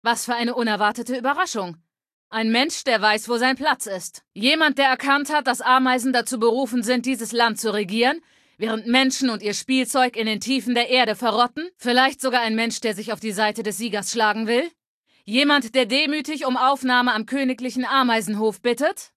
Datei:Femaleadult01default ms02 ms02superheroexplain1 0003c8c8.ogg
Fallout 3: Audiodialoge